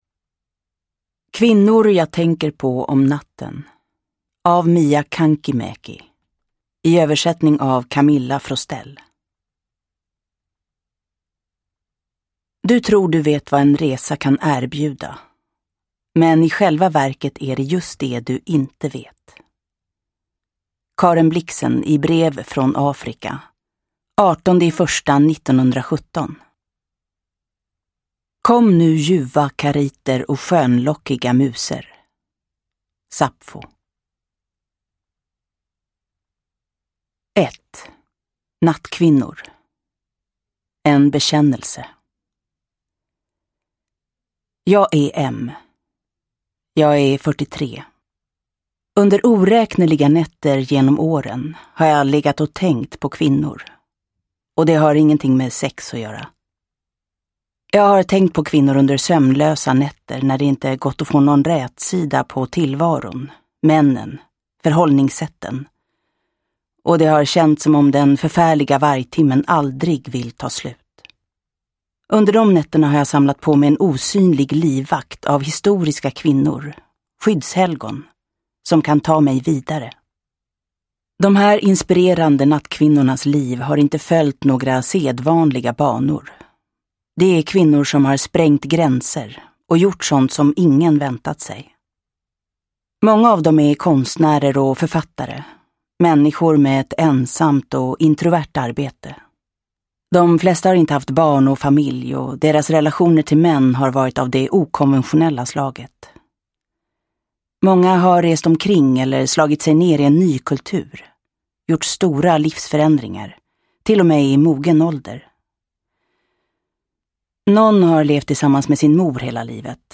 Kvinnor jag tänker på om natten – Ljudbok
Uppläsare: Jessica Liedberg